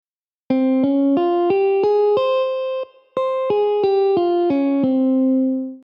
1-b2-4-5-b6
(C-Db-F-G-Ab-C)